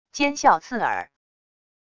尖啸刺耳wav音频